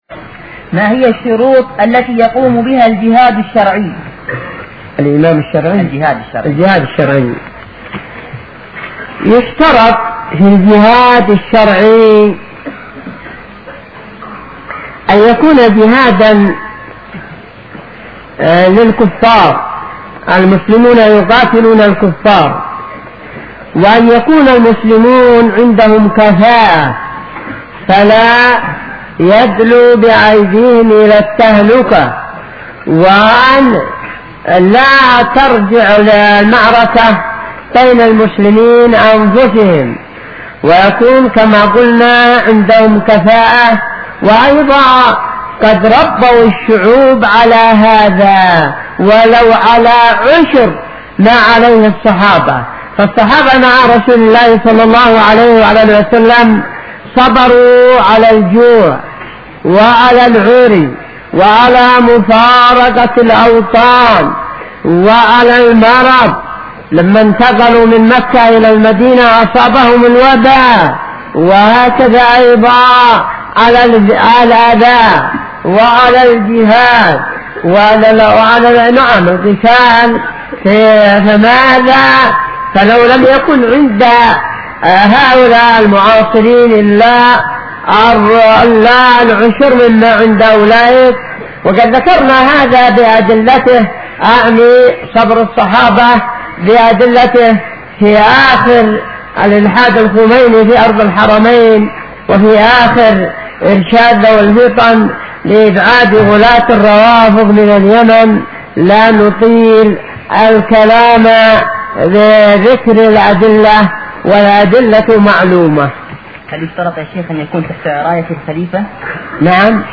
muqbel-fatwa2675.mp3